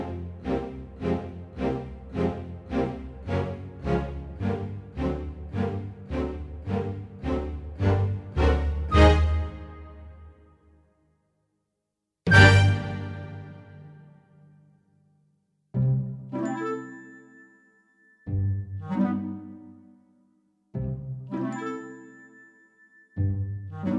With Speeches Cut Musicals 2:40 Buy £1.50